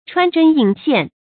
注音：ㄔㄨㄢ ㄓㄣ ㄧㄣˇ ㄒㄧㄢˋ
穿針引線的讀法